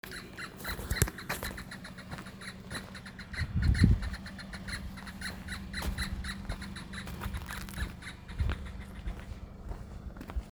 Falcãozinho-cinza (Spiziapteryx circumcincta)
Nome em Inglês: Spot-winged Falconet
Localidade ou área protegida: Reserva de Biósfera Ñacuñán
Condição: Selvagem
Certeza: Fotografado, Gravado Vocal
Halconcito-gris_1.mp3